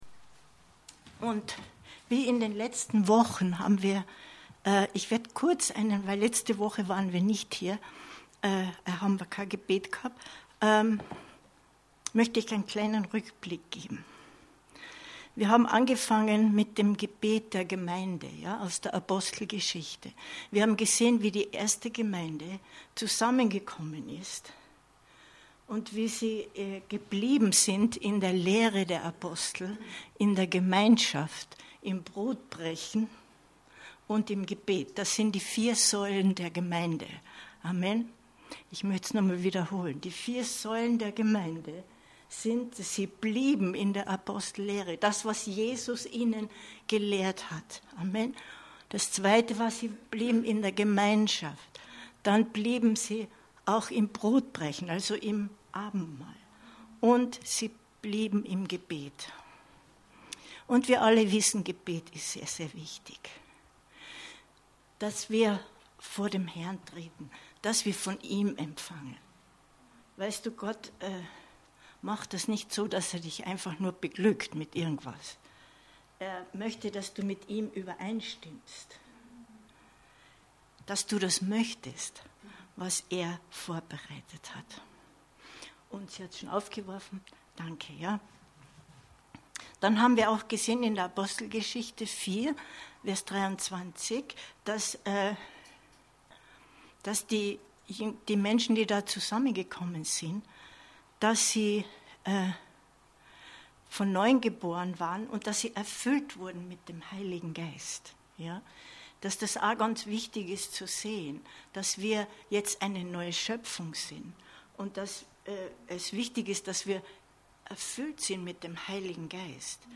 Aufnahme des Bibelabends vom Mittwoch, 07.09.2022
Predigt